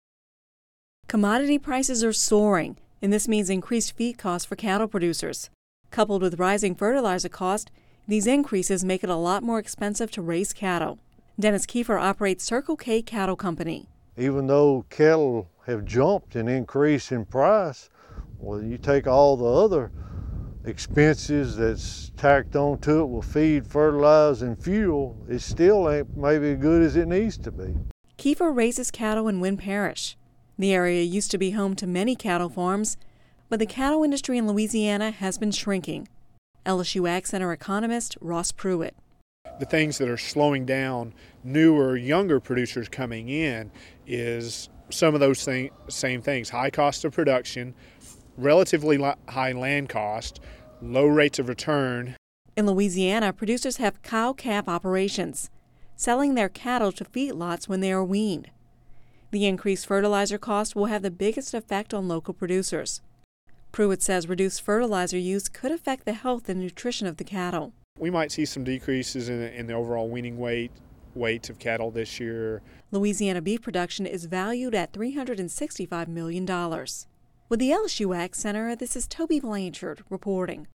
(Radio News 02/28/11) Commodity prices are soaring, and that means increased feed costs for cattle producers. Coupled with rising fertilizer costs, these increases make it a lot more expensive to raise cattle.